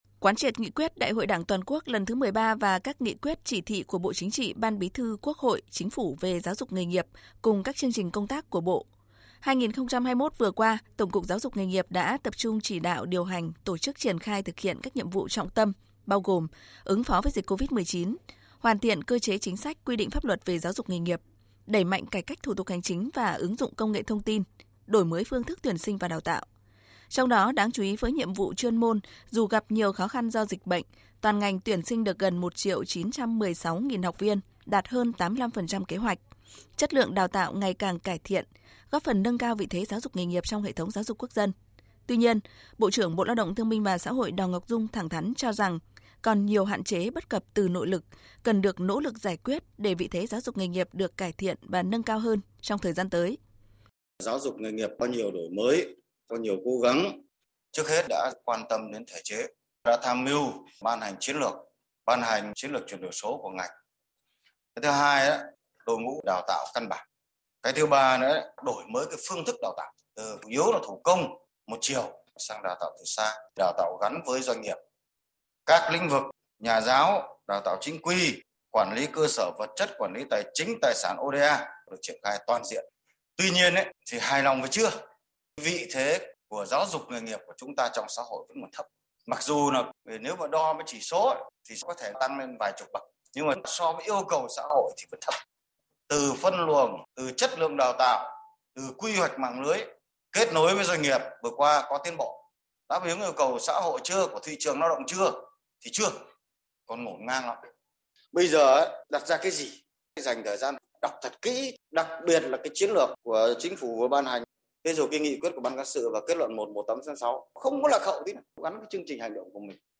THỜI SỰ Tin thời sự